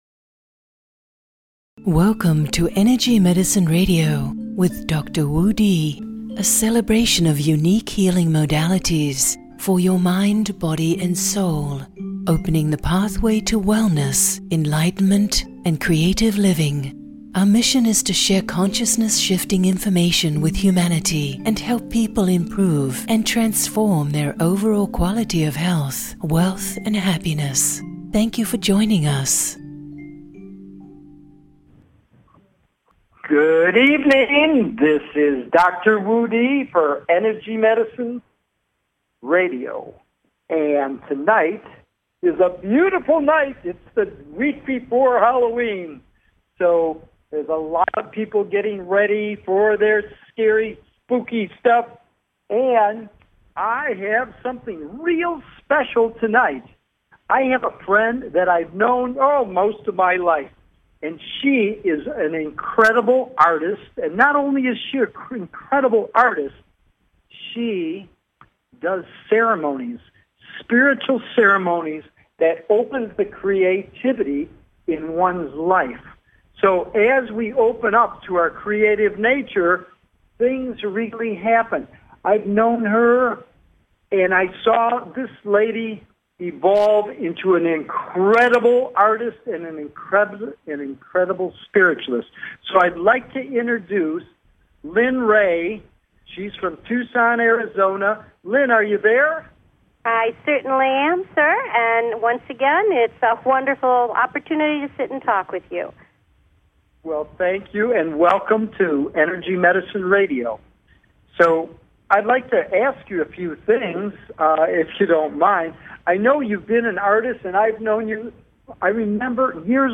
Headlined Show, Energy Medicine Radio October 28, 2014